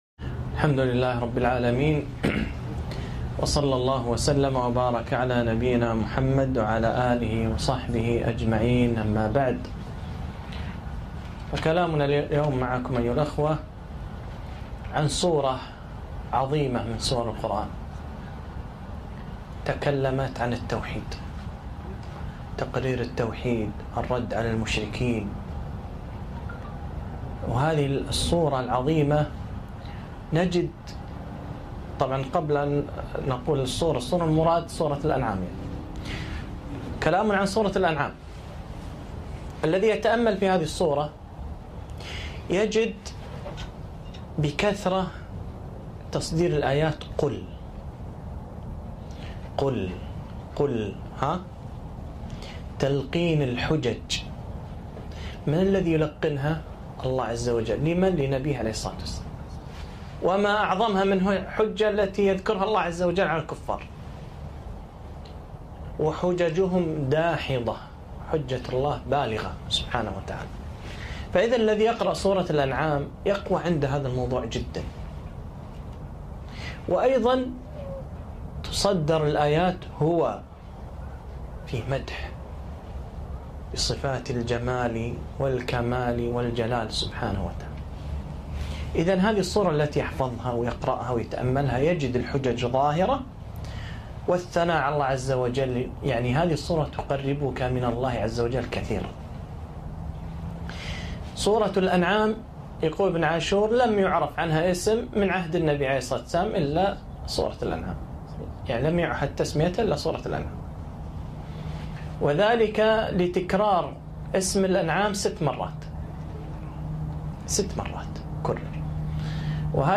محاضرة - تأملات من سورة الانعام تأملات قرآنية